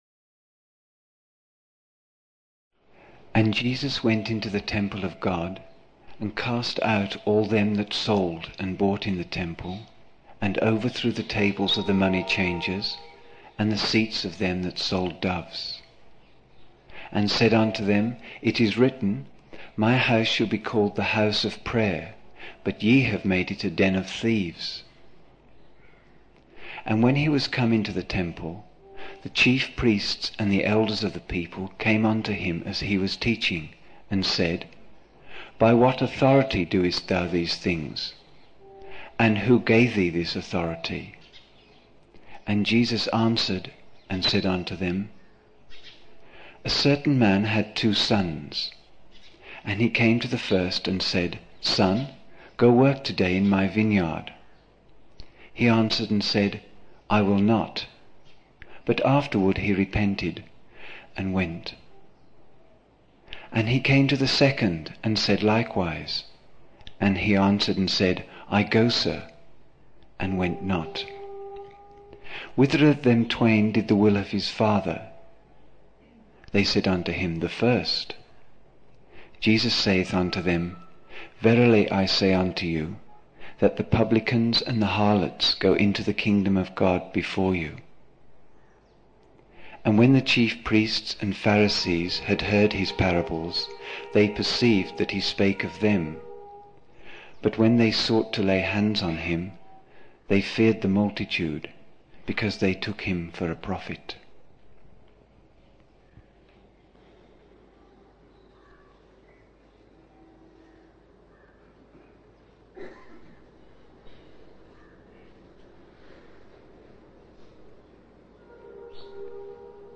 15 December 1975 morning in Buddha Hall, Poona, India